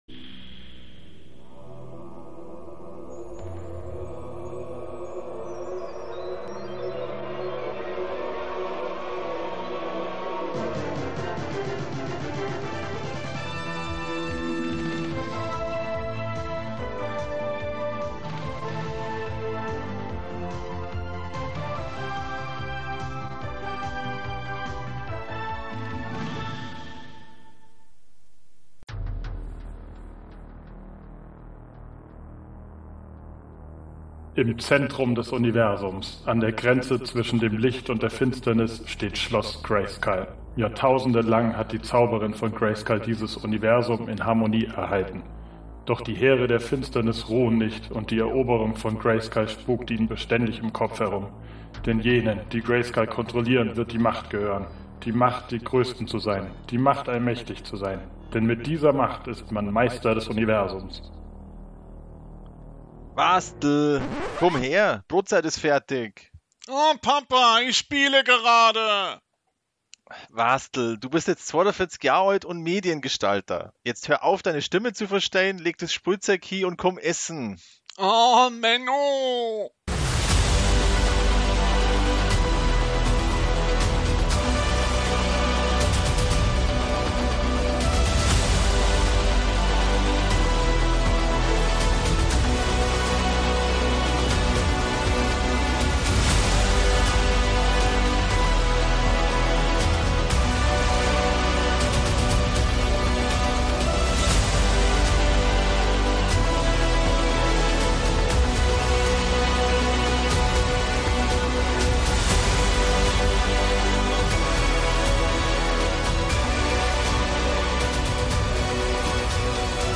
Der Film kommt nie! Aber das Hörspiel, das auf jeden Fall.